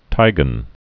(tīgən) also ti·glon (-glən)